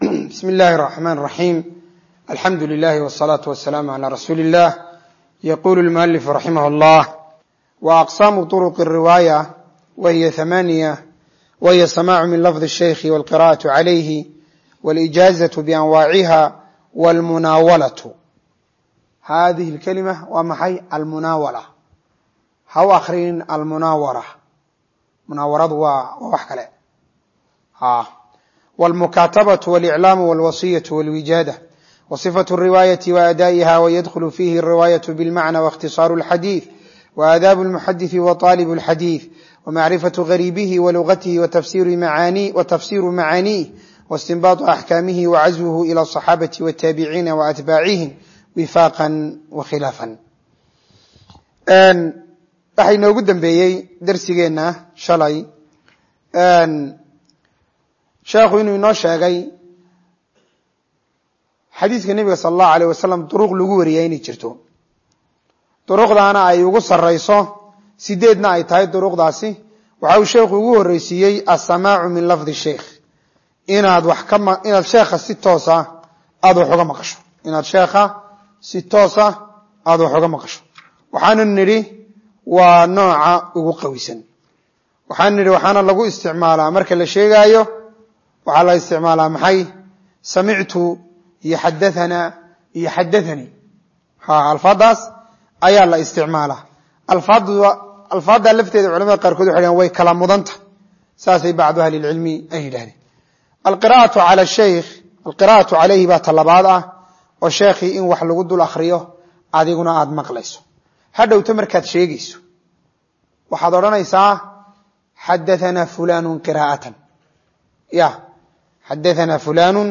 Sharaxa kitaabka Tadkiratu Ibnu Mulaqin - Darsiga 11aad - Manhaj Online |
Dawratu Ta-siiliyah Ee Masjidu Rashiid Hargeisa